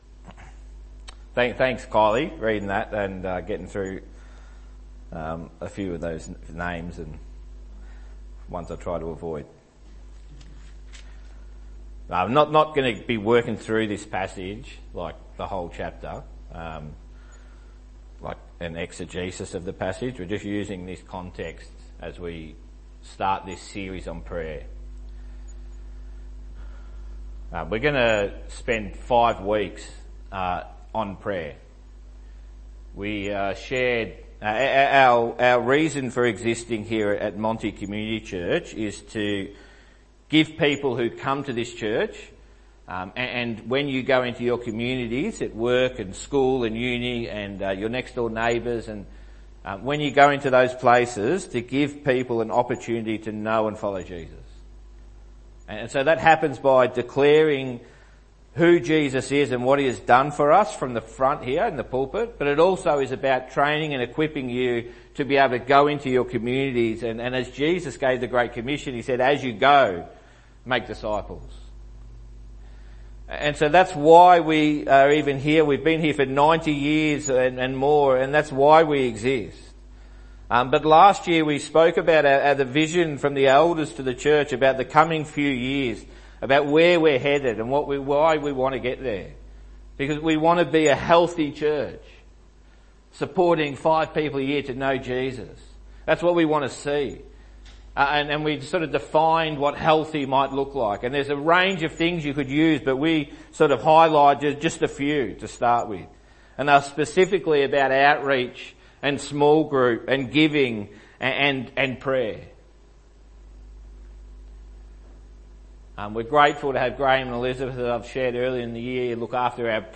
Messages from Monty's service's.